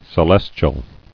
[ce·les·tial]
Ce*les"tial*ly, adv.